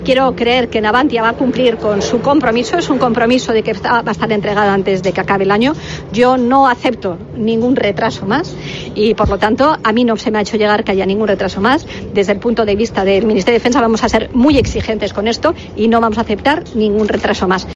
Robles, que ha asistido al acto solemne de apertura del curso académico 2023-2024 en la Academia General del Ejército del Aire (AGA) en San Javier, a preguntas de los medios, ha dicho que no tiene constancia de ello.